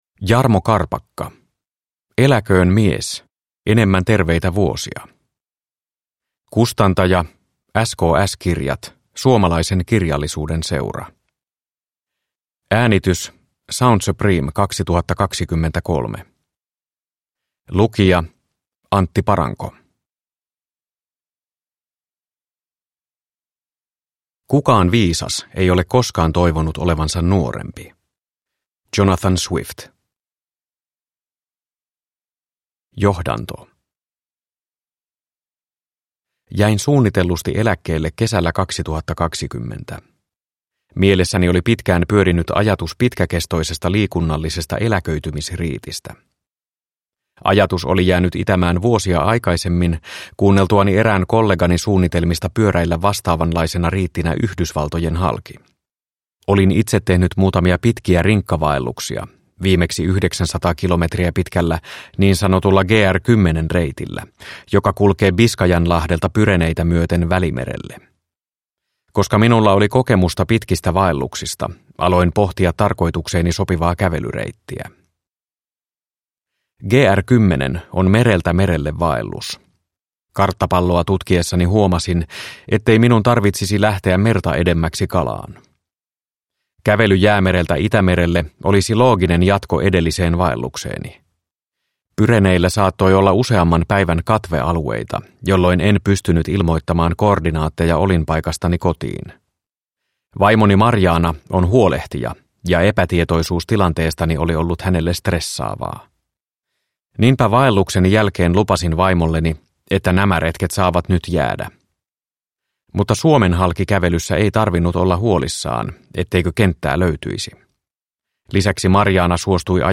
Eläköön mies! – Ljudbok – Laddas ner